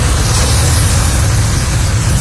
flamethrower sound loop
flameLoop.ogg